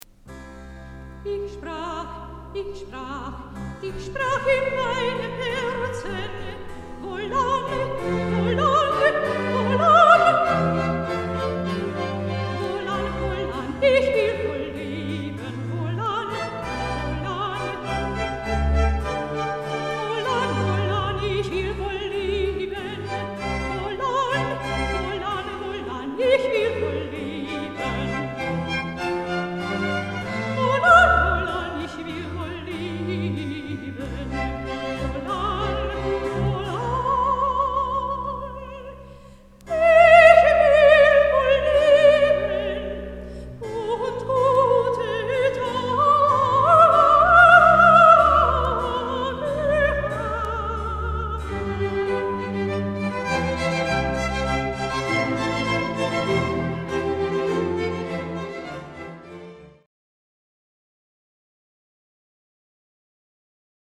3 viulua ja bc+